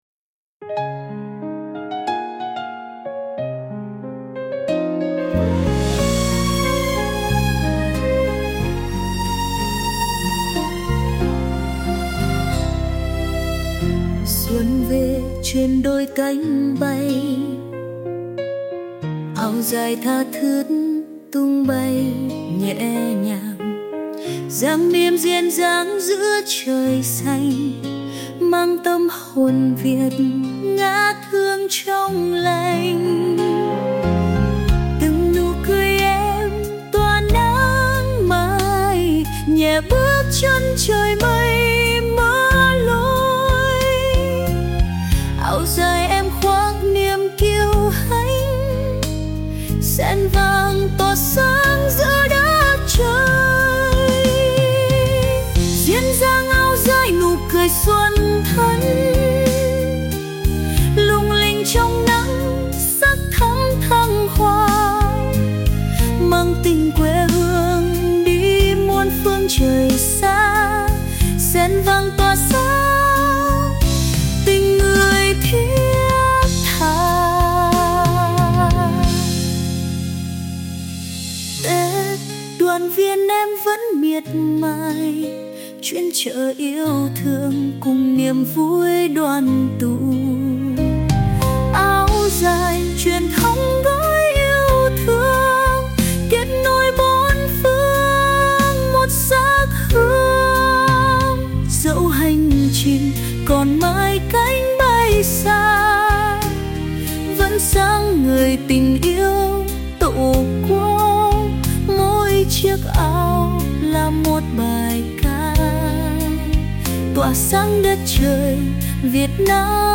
ra đời như một bản tình ca ngọt ngào
bài hát mang âm hưởng tươi sáng, tràn đầy sức sống của mùa xuân. Từng ca từ nhẹ nhàng nhưng sâu lắng, từng giai điệu bay bổng như đôi cánh mang theo tình yêu quê hương đi khắp bốn phương trời.
Cất lên trong những nhịp điệu rộn ràng